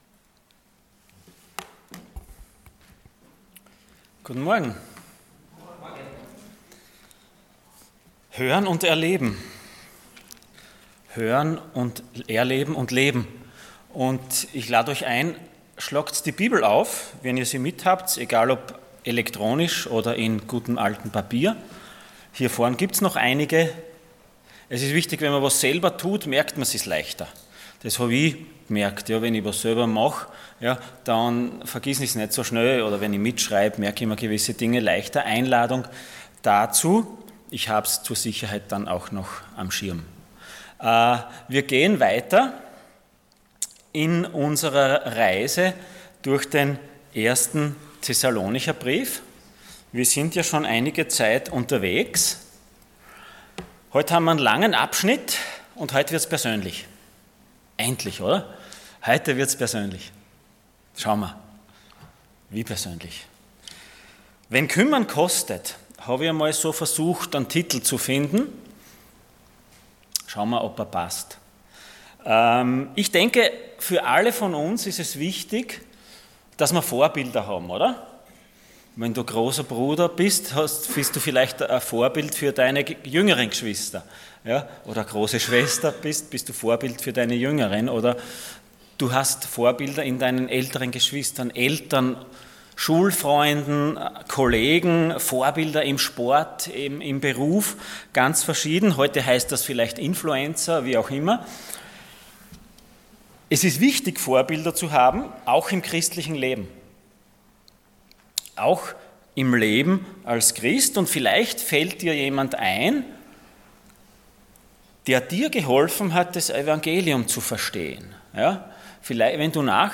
Gnade und Dank Passage: 1 Thessalonians 2:17-3:13 Dienstart: Sonntag Morgen %todo_render% Wenn kümmern kostet…